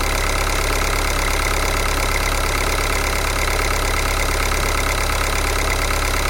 Train 002
描述：Diesel locomotive at railstation Varna.
标签： train sound railway engine_sound diesel field_recording
声道立体声